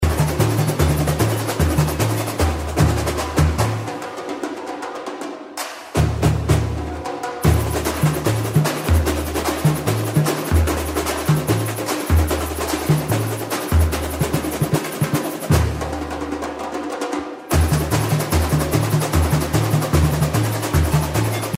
Breaks